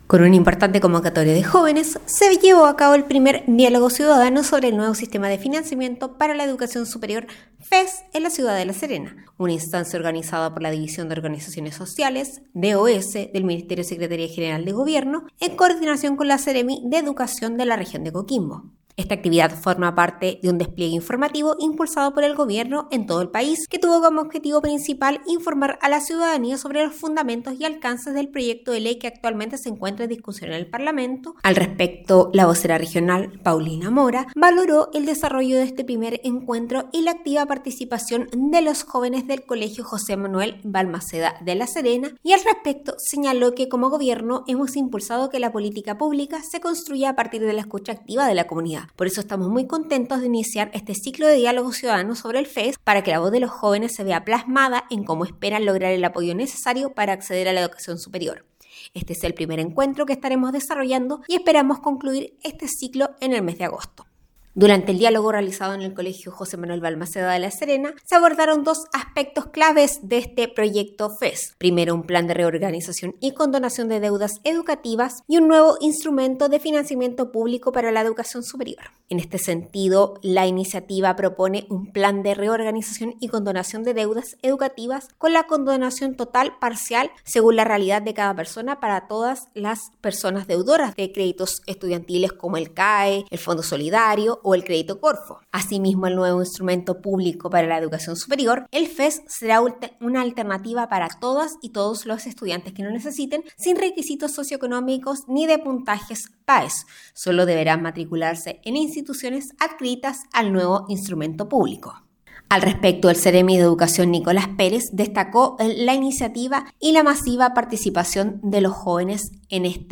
DESPACHO-DIALOGO-FES-LA-SERENA.mp3